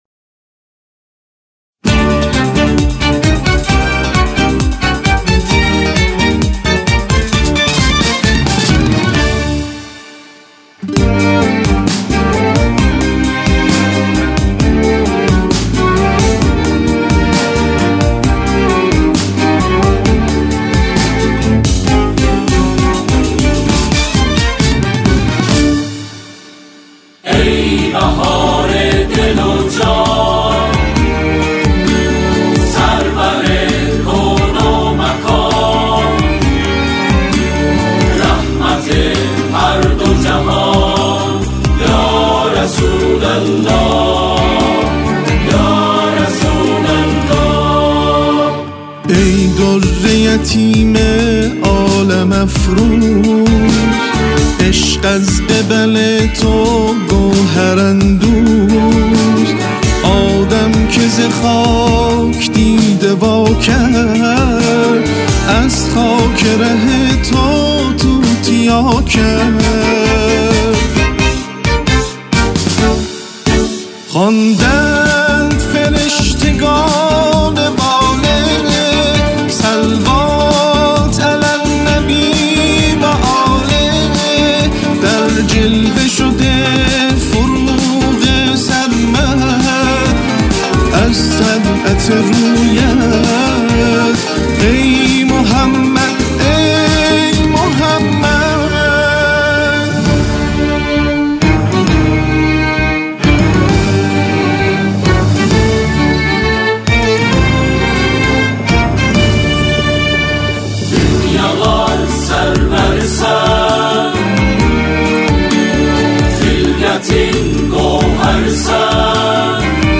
سرودهای ۱۷ ربیع الاول